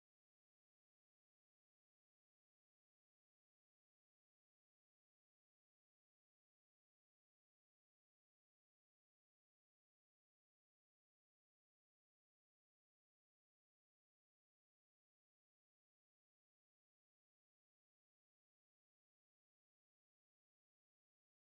Balladen: Brombeerenpflücken
Tonart: G-Dur
Taktart: 4/4
Tonumfang: große Dezime
Besetzung: vokal